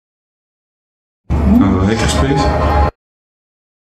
Hackerspace-woord.mp3